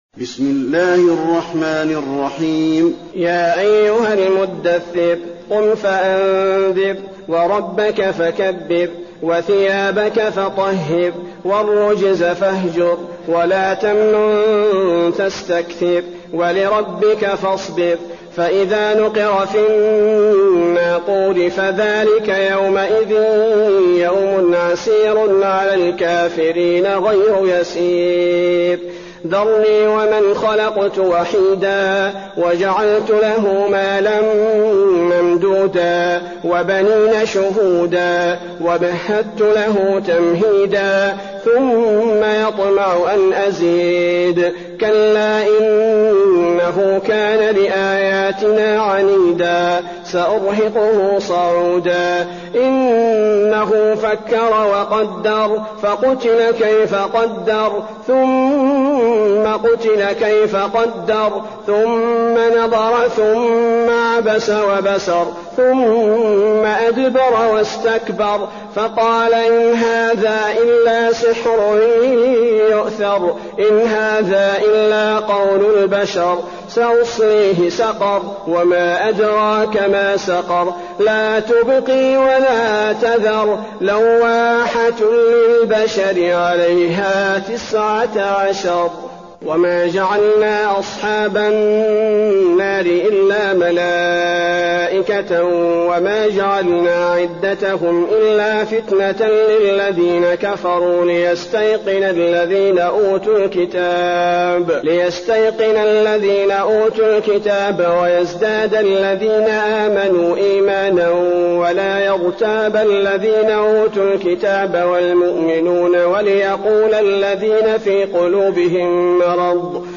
المكان: المسجد النبوي المدثر